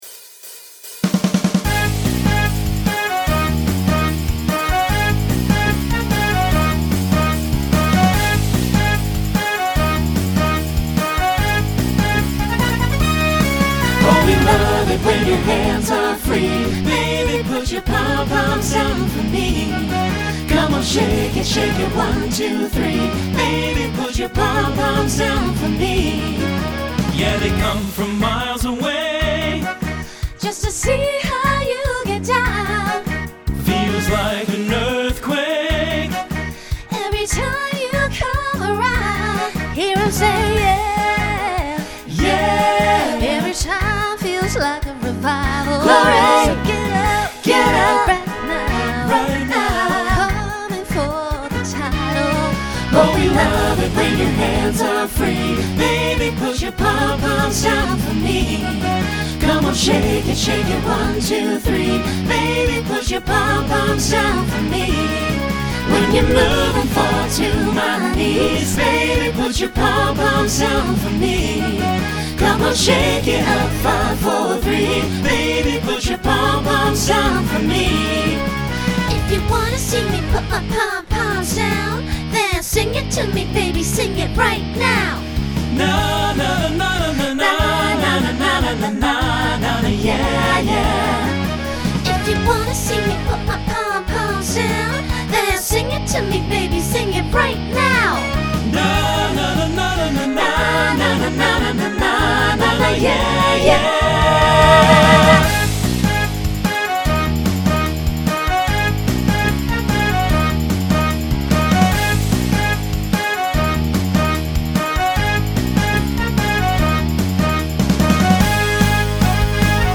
Genre Pop/Dance
Voicing SATB